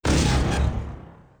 dock.wav